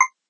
ping_5.ogg